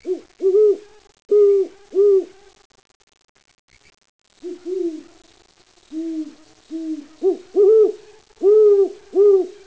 Great Horned Owl Bubo virginianus (Strigidae)
Call
grhowlcornell.wav